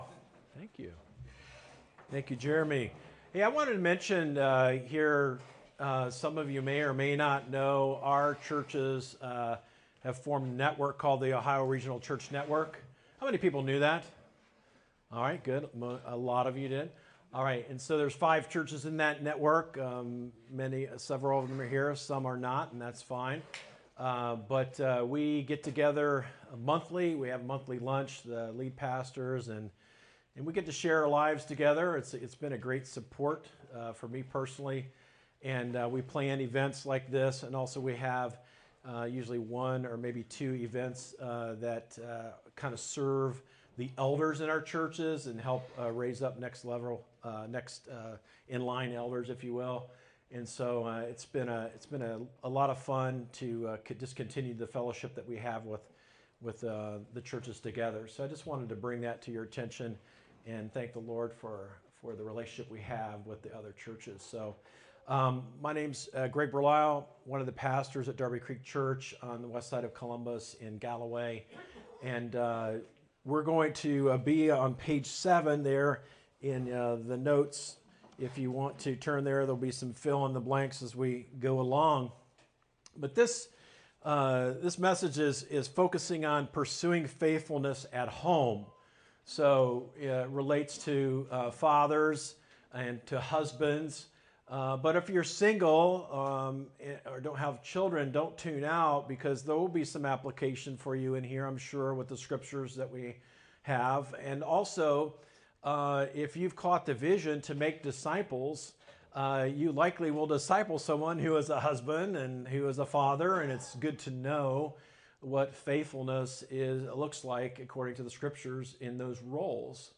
Session 2 of Faithful Masculinity men’s retreat.